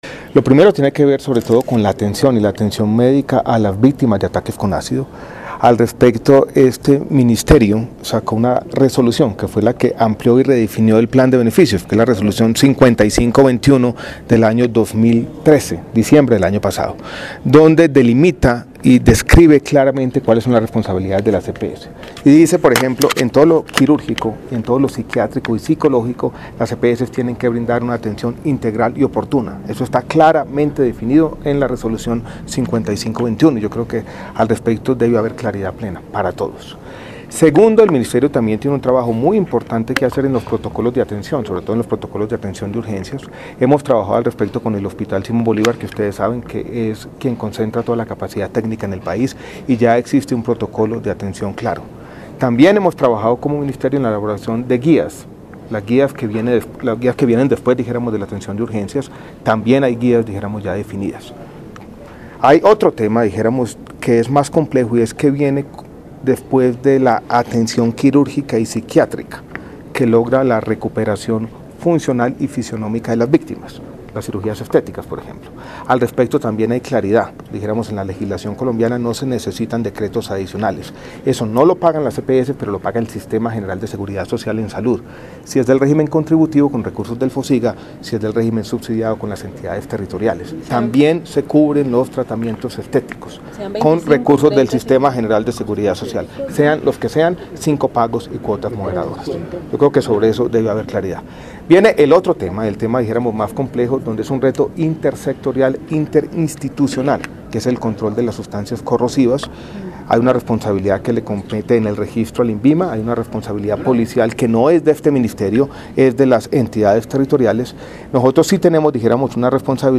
Audio, Ministro Alejandro Gaviria, habla sobre la atención médica a víctimas de ataque con ácido